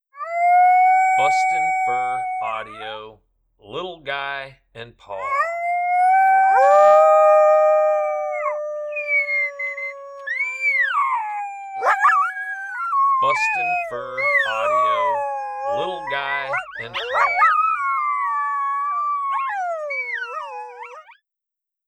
1 Year old pup and his daddy, Apollo, duet howling together.
• Product Code: howls